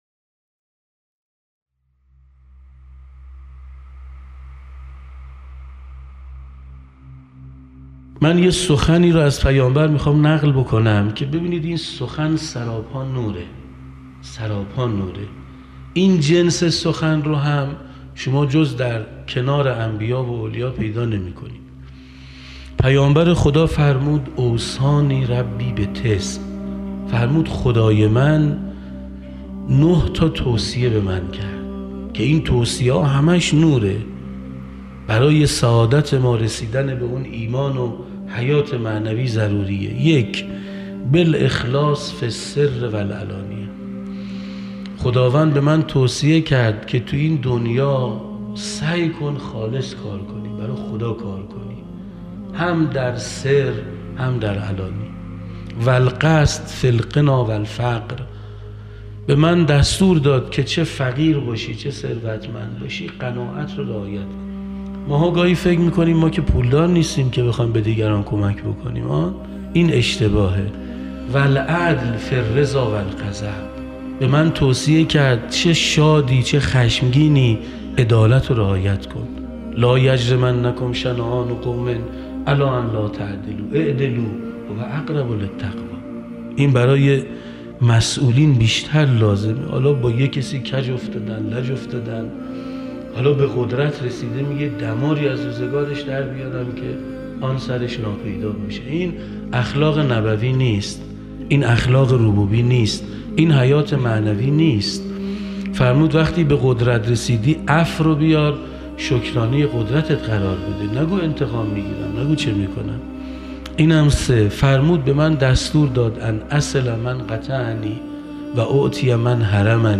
در حرم مطهر رضوی